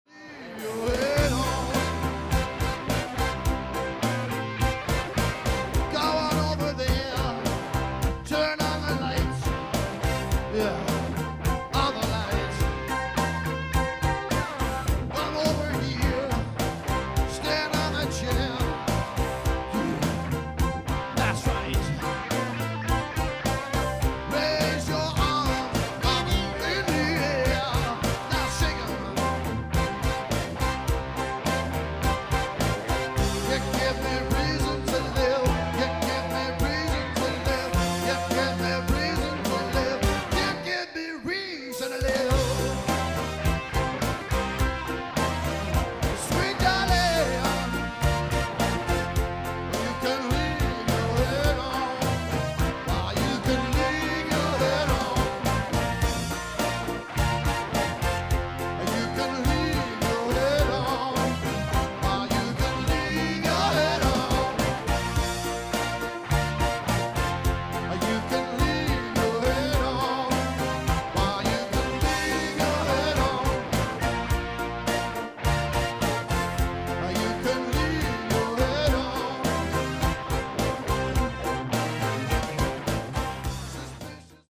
two piece band
Cover